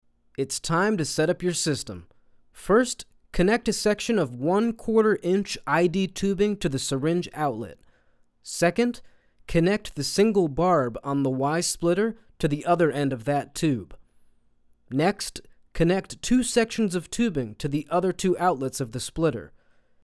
Male
English (North American)
Yng Adult (18-29), Adult (30-50)
My voice is youthful and conversational.
My tone is warm and engaging
E-Learning
A Short Instructional Clip
0920E_Learning_DEMO.mp3